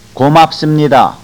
Go Mahp Soom Nee Dah- Thank you
go_mahm_soom_nee_dah.au